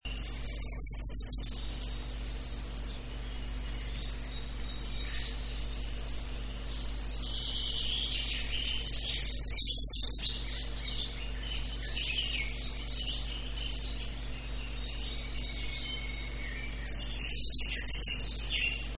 Śpiew pełzacza ogrodowego składa się ze wznoszących się, wysoko brzmiących strof.